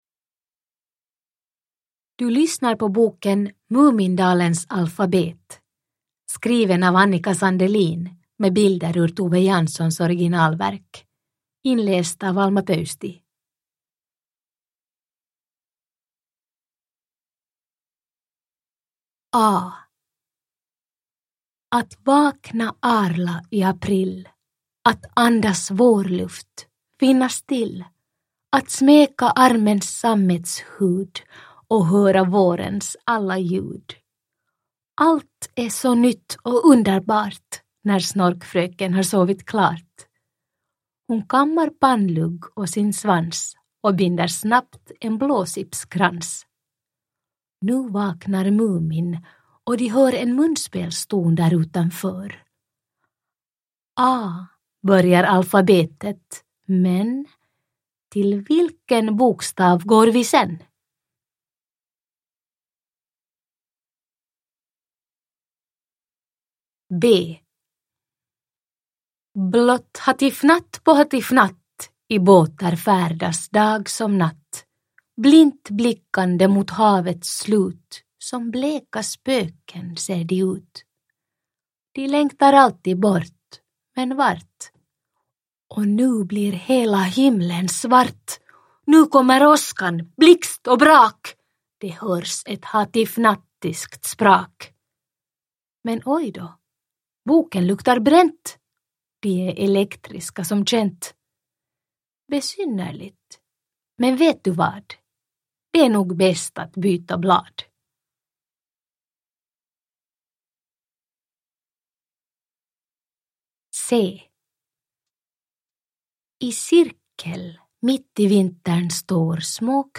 Mumindalens alfabet – Ljudbok
Uppläsare: Alma Pöysti